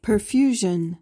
PRONUNCIATION:
(puhr-FYOO-zhuhn)